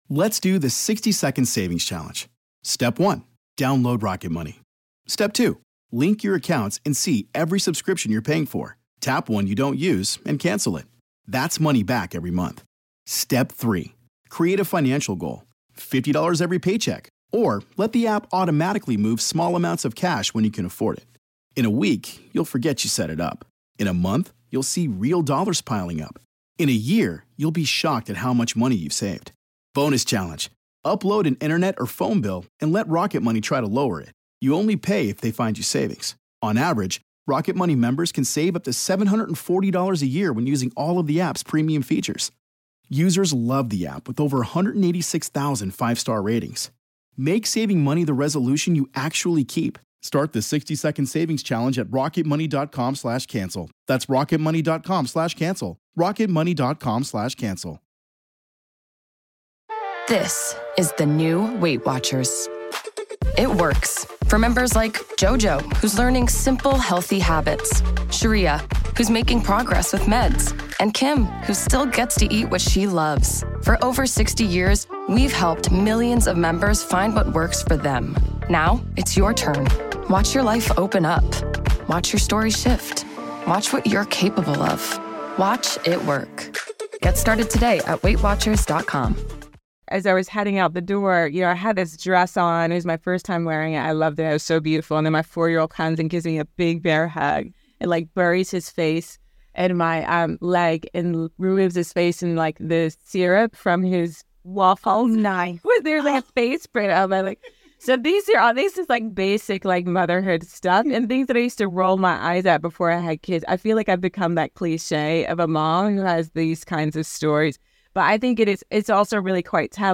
Vick Hope, multi-award winning TV and BBC Radio 1 presenter, author and journalist, is the host of season six of the Women’s Prize for Fiction Podcast.